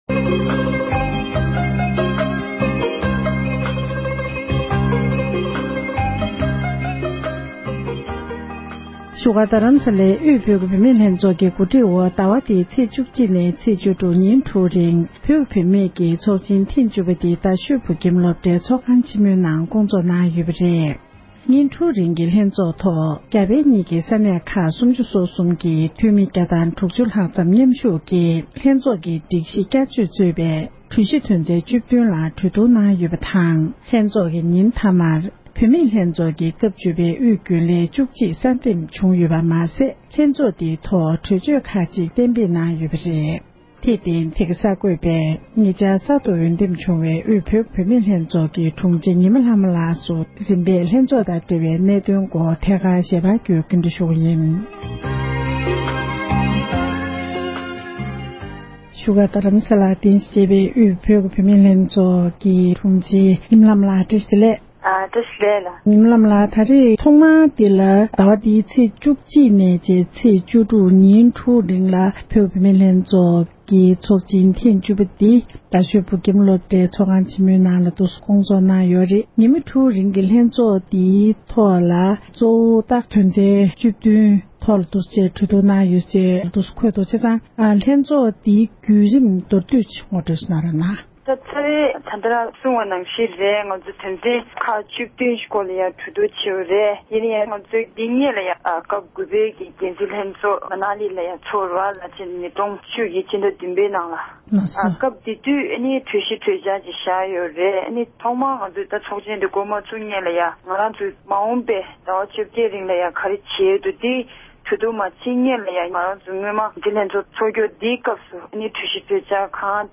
ཞལ་པར་བརྒྱུད་གནས་འདྲི་ཞུས་པ་ཞིག